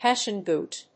アクセント・音節Hés・sian bóot